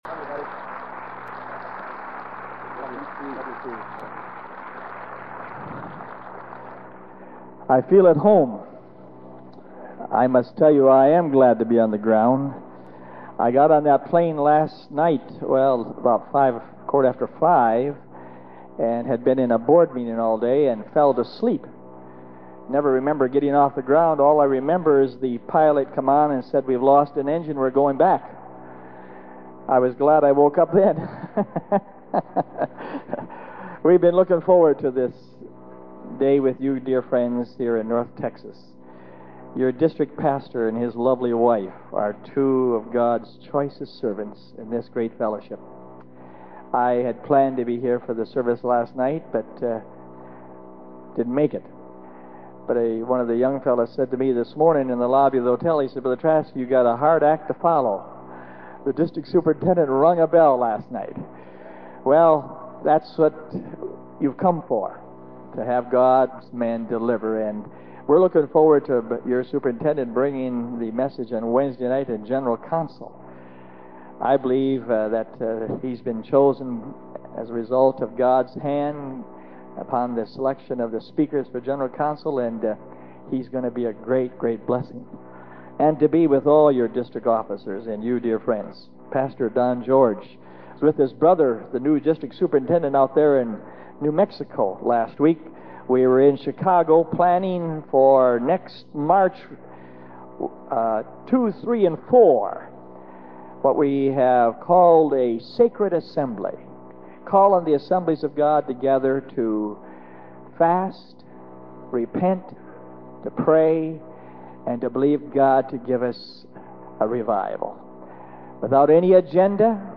* The back side of this tape was blank. We have most, but not all, of the message.